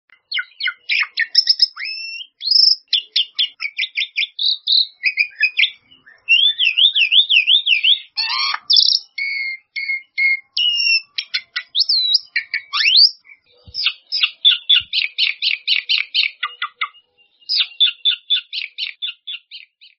Song of the Black-winged Starling
blacked-winged-bali-starling-song.mp3